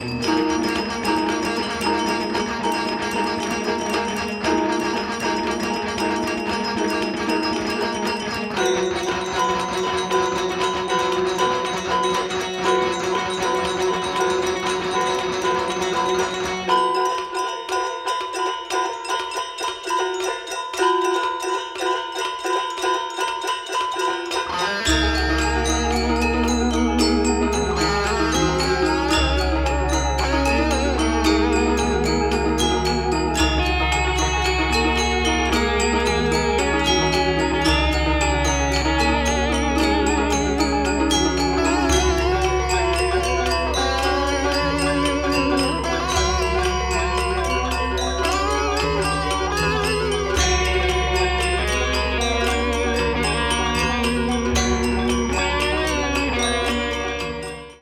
electric guitars